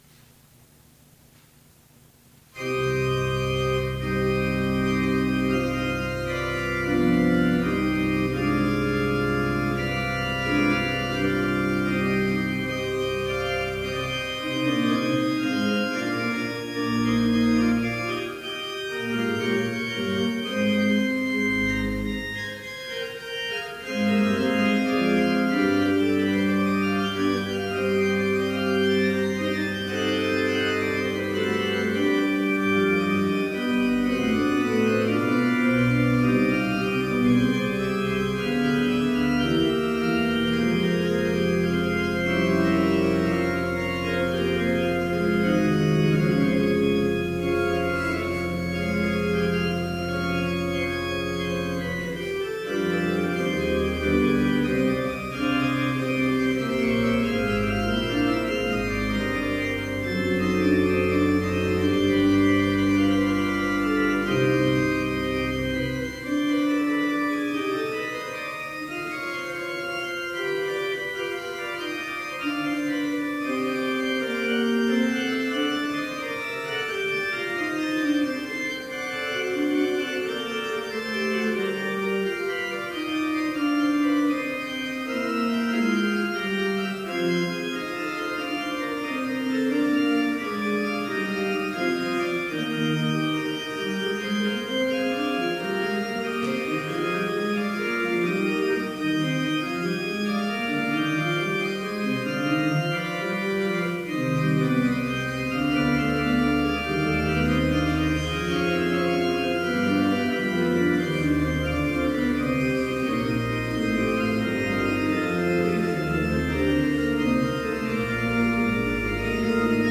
Complete service audio for Chapel - November 1, 2018